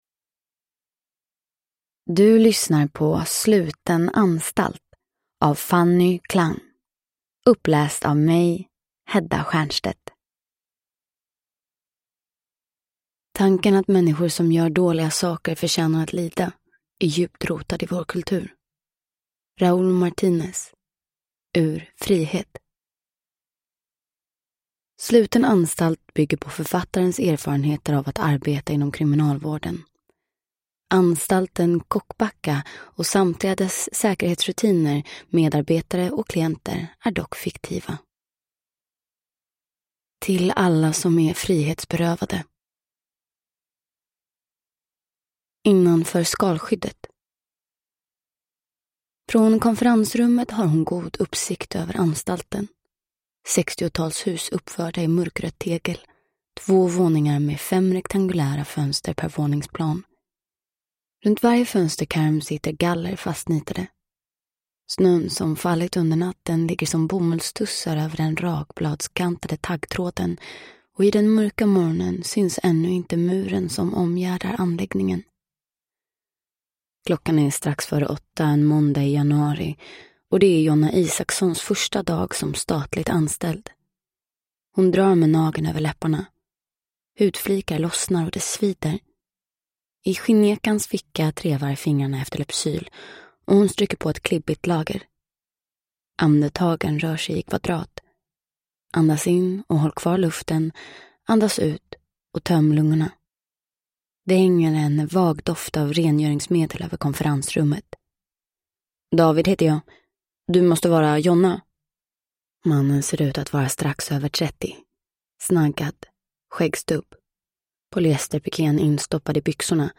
Uppläsare: Hedda Stiernstedt
Ljudbok